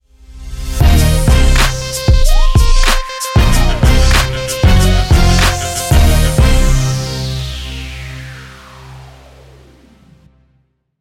Музыкальная отбивка для видеомонтажа